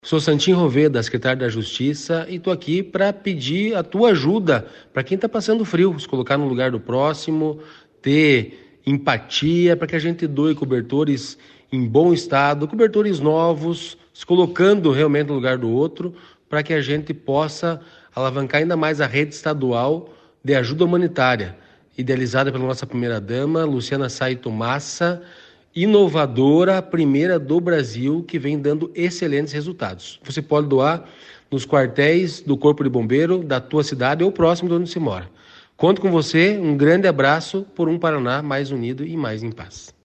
Sonora do secretário Estadual da Justiça e Cidadania, Santin Roveda, sobre a campanha de arrecadação de cobertores Aquece Paraná